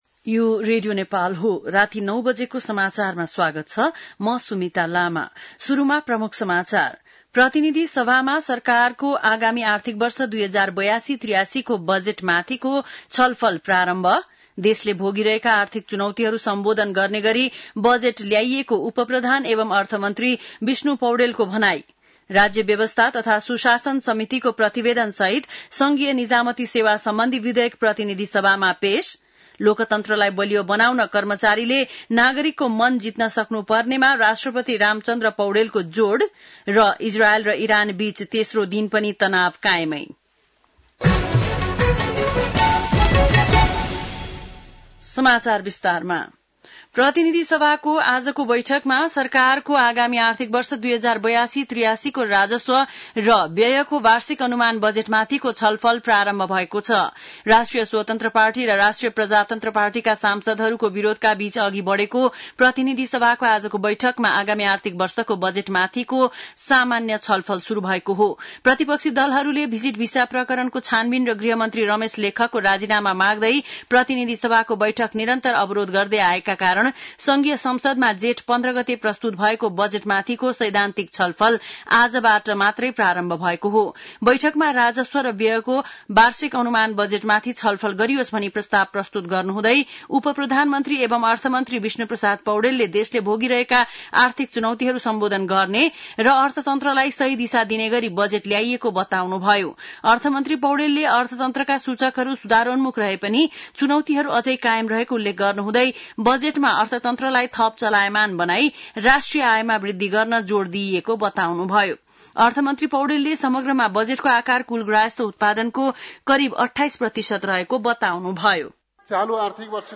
बेलुकी ९ बजेको नेपाली समाचार : १ असार , २०८२
9-pm-nepali-news-1-2.mp3